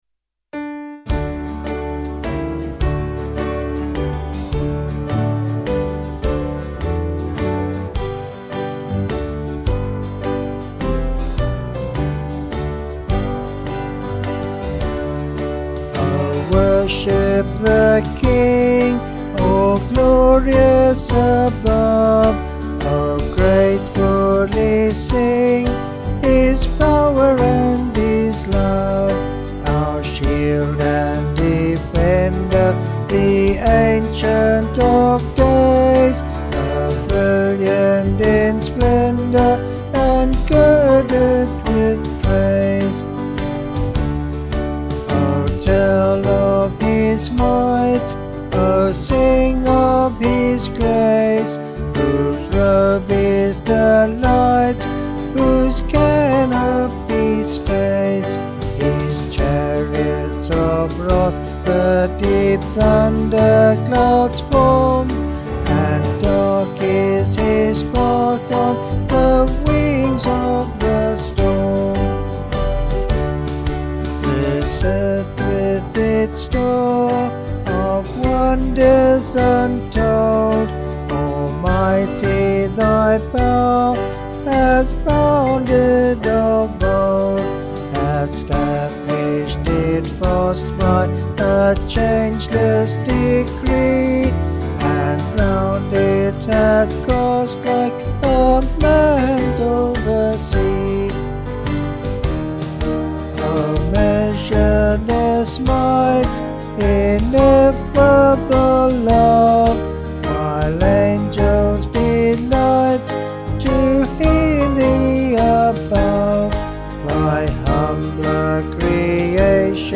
Vocals and Band   263.3kb Sung Lyrics